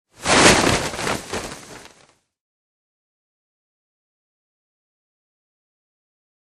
Parachute: Opening Flaps.